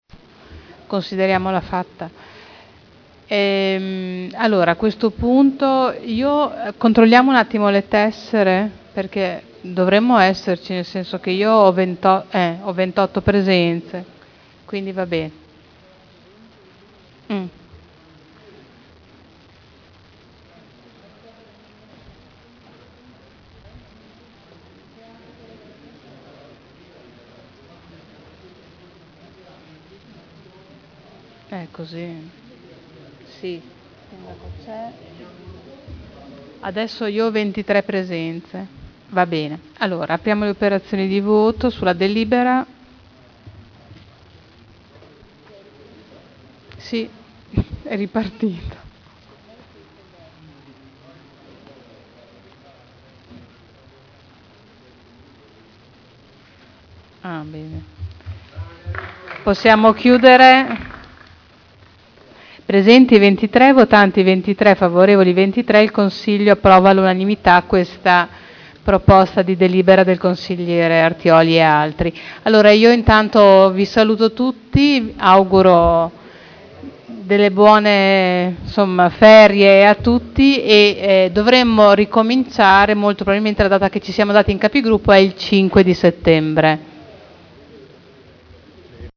Presidente — Sito Audio Consiglio Comunale
Audio Consiglio Comunale